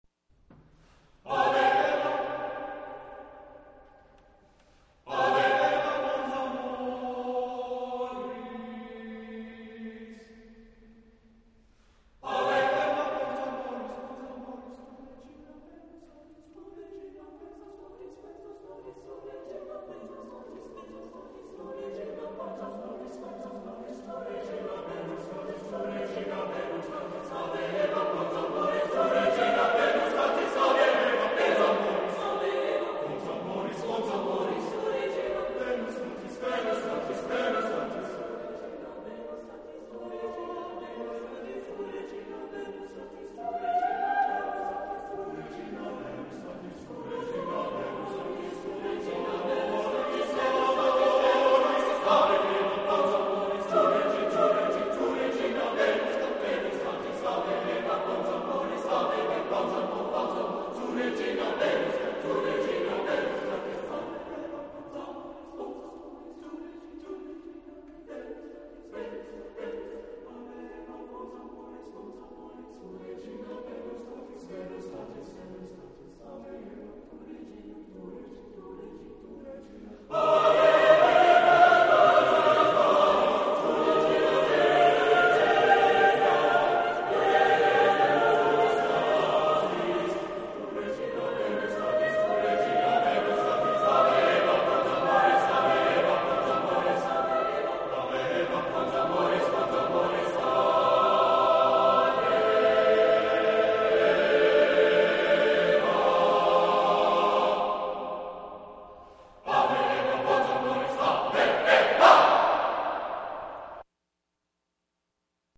Voicing: SSAATTBB a cappella